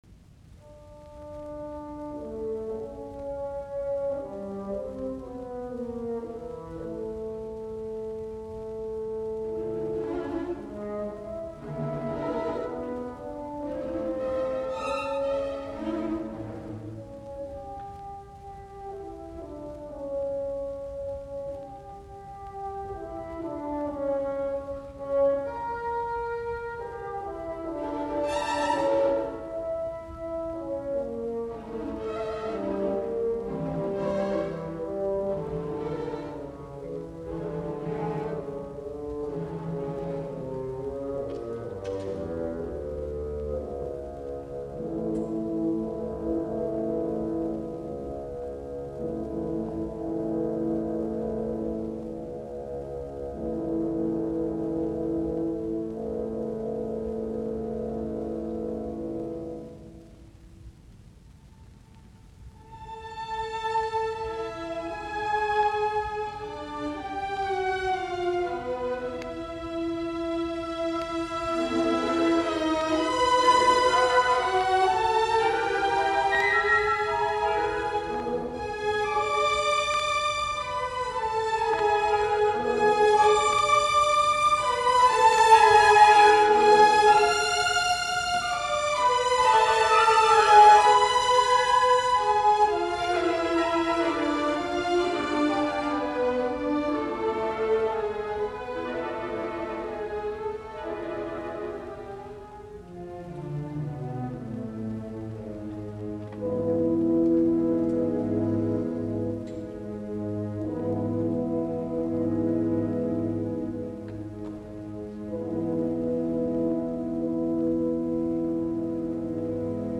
Andante sostenuto